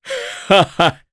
Gau-Vox_Happy2_jp.wav